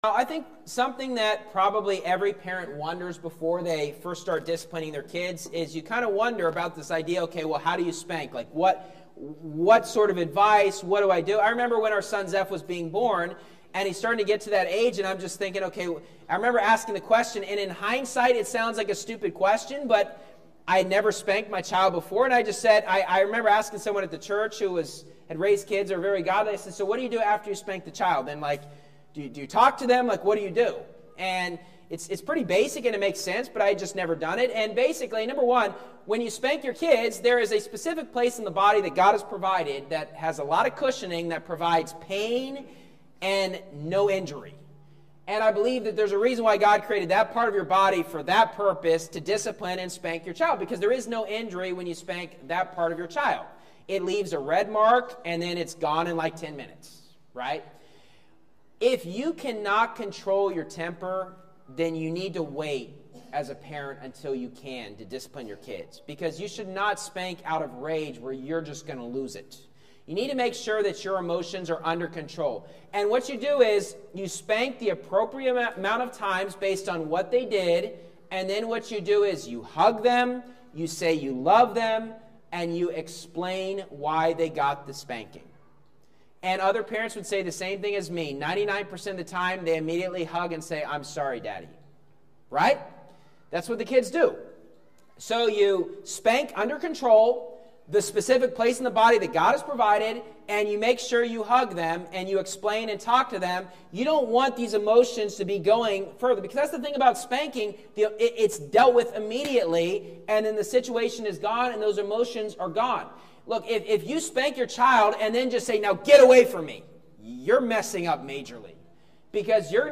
Sermon Clips Bro.